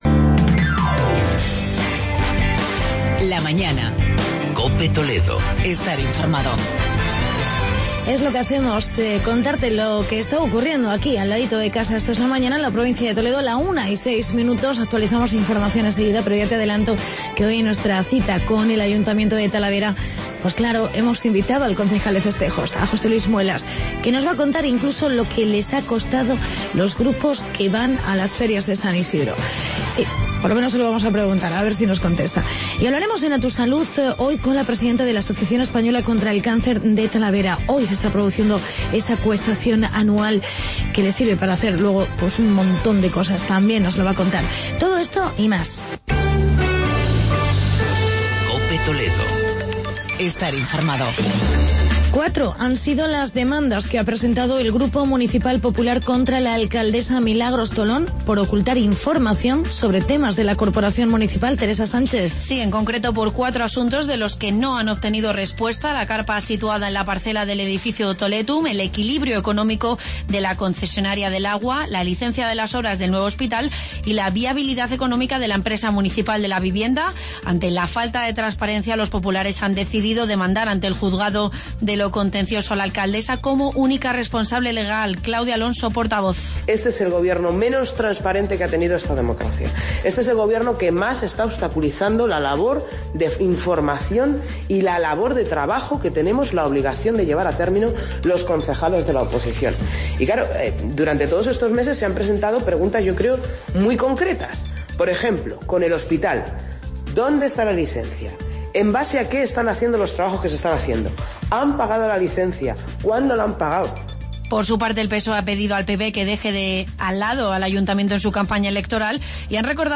Entrevista con el concejal José Luis Muelas sobre las Ferias de San Isidro en Talavera y en "A Tu Salud"...